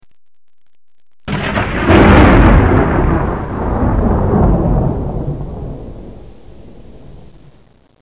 打雷的声音 雷声音效
【简介】： 打雷的声音、雷声音效